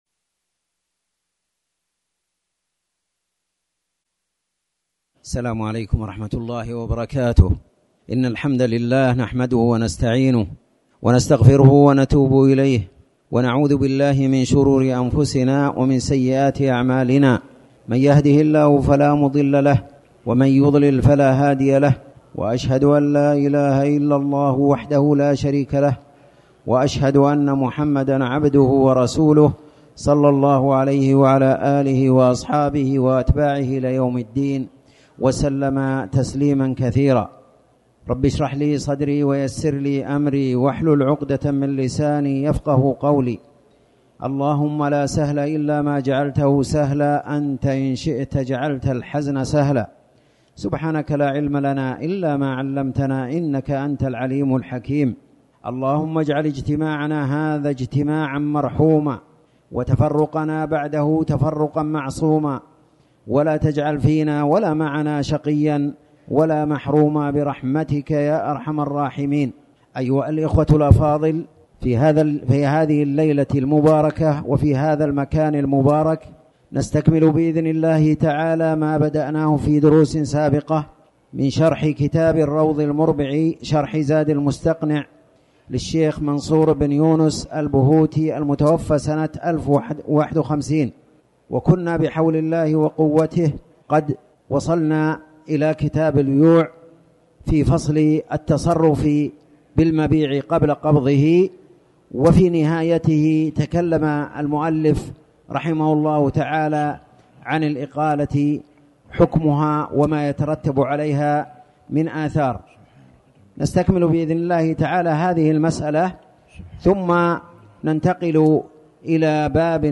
تاريخ النشر ٤ ربيع الثاني ١٤٤٠ هـ المكان: المسجد الحرام الشيخ